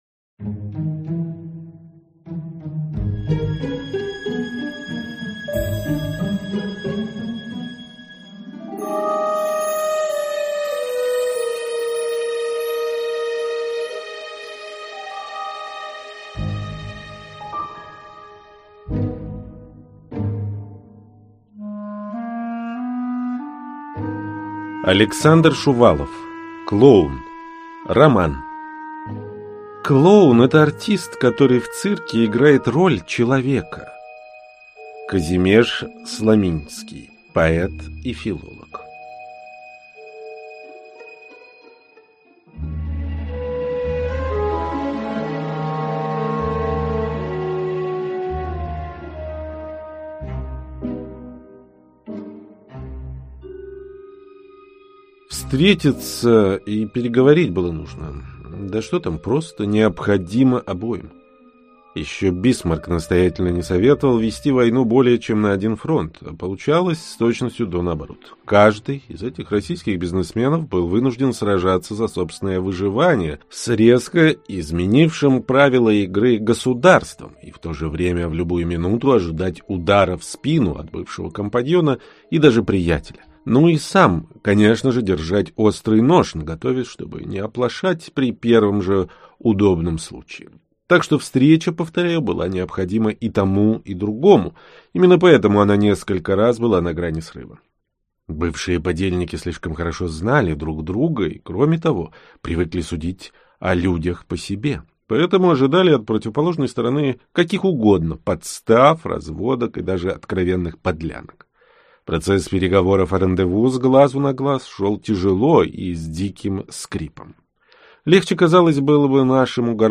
Аудиокнига Клоун | Библиотека аудиокниг